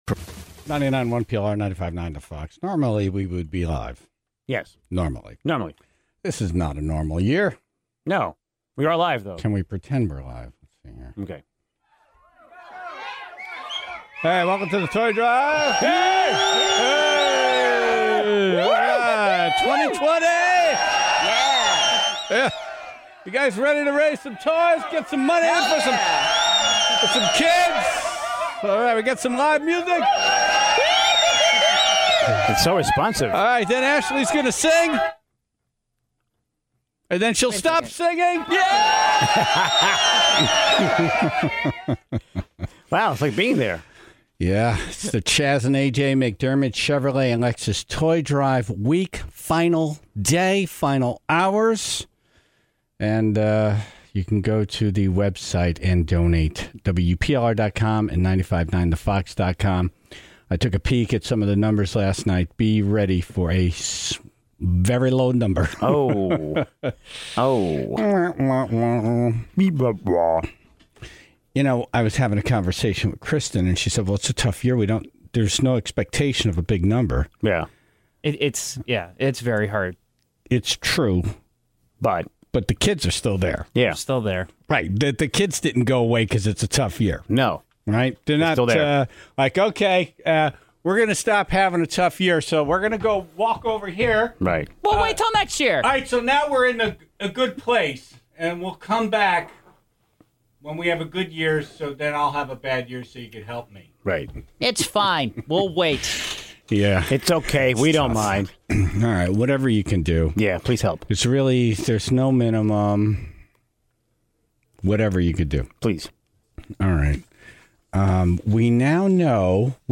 (19:09) Mayor Pete Hess of Naugatuck contributed to the Toy Drive with a spoken rendition of a Shirley Temple song, and admitted to having his eye on a specific auction item.